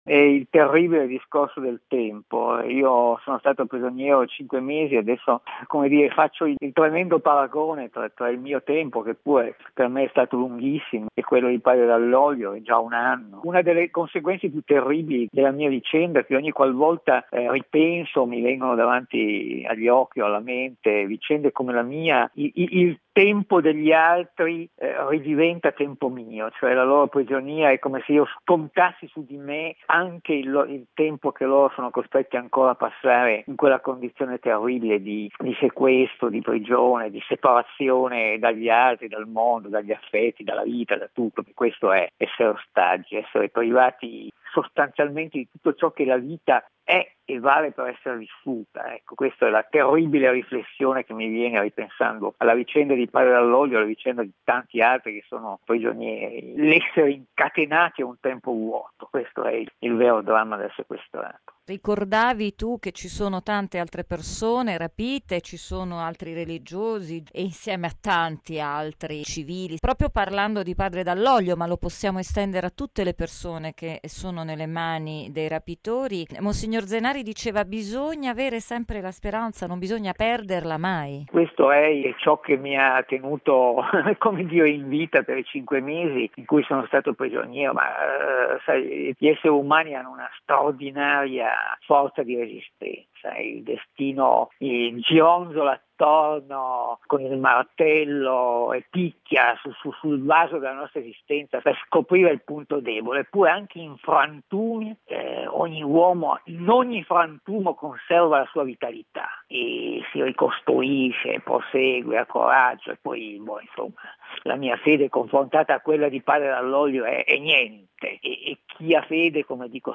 Quando padre Dall’Oglio veniva sequestrato, il giornalista del quotidiano “La Stampa”,  Domenico Quirico, era già stato rapito.